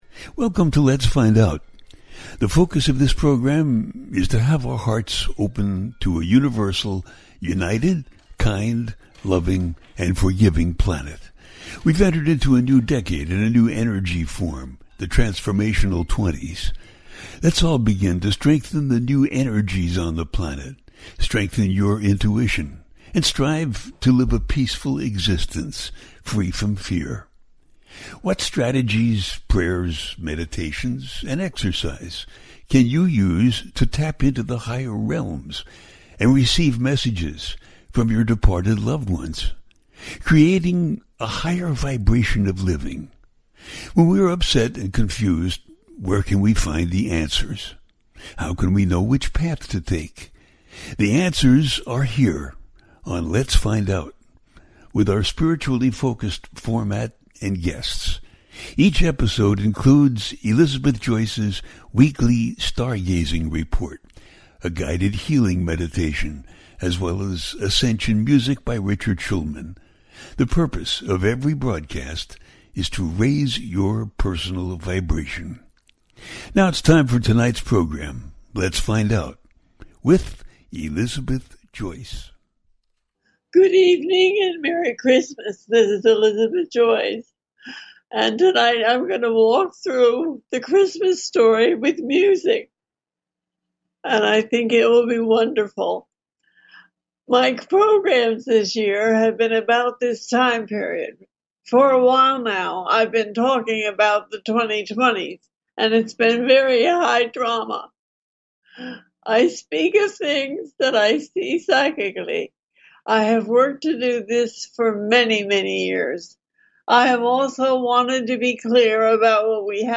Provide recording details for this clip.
The listener can call in to ask a question on the air.